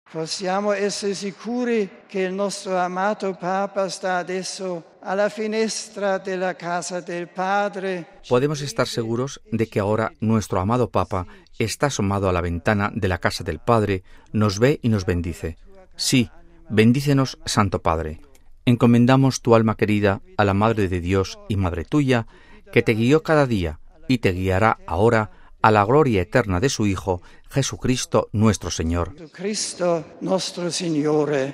Empezando por las palabras con las que el entonces decano del Colegio Cardenalicio, cardenal Joseph Ratzinger - hoy Benedicto XVI –sellaba la homilía de la Misa Exequial por el Pontífice Karol Wojtyla, cuya alma encomendaba a la Madre de Dios.